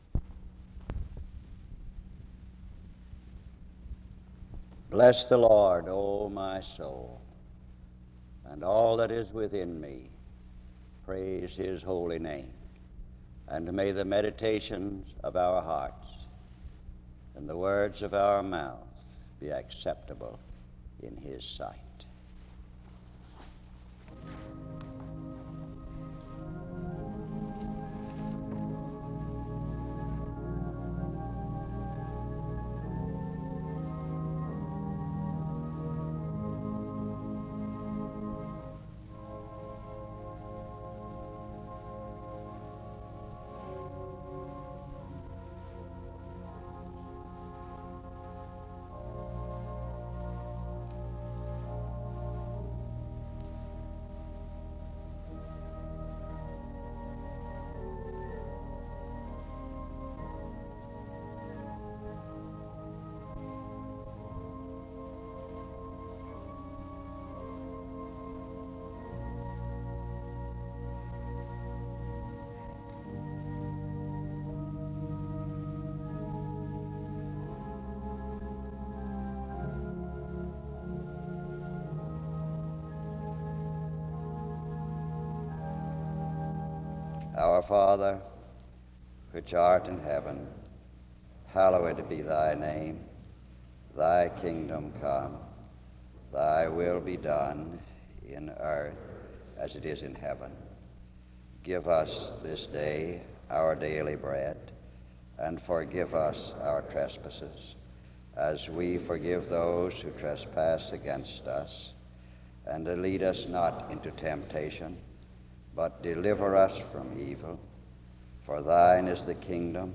The service ends with a prayer and a song (57:51-end).